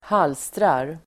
Uttal: [²h'al:strar]